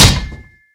latest / assets / minecraft / sounds / mob / zombie / metal3.ogg
metal3.ogg